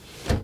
Door_close.R.wav